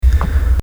Одиночные щелчки в записи
Нееее...... там похоже на акустический щелчок.. мне кажется, что когда проблемы цифровые, должны быть или чистые выпадения до нуля, или с интерполяцией, а тут вполне себе обычный переходный процесс.....